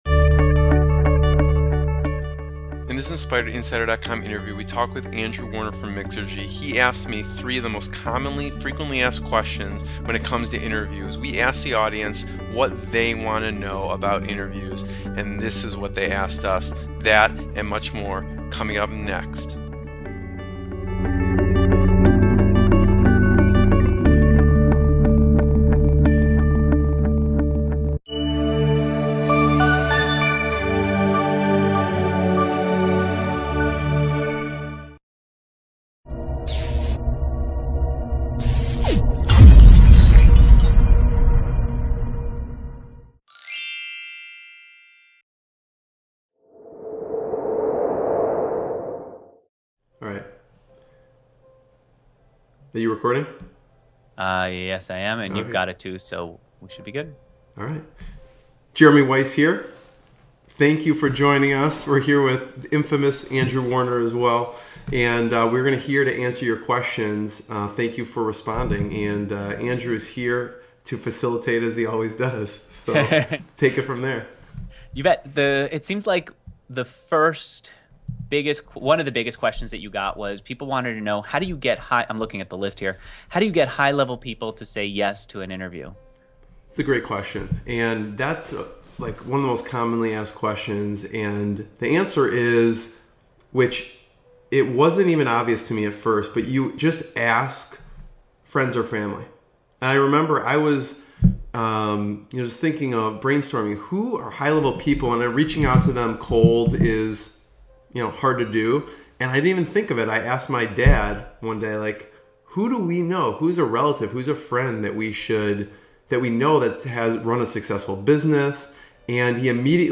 What will you learn in this interview?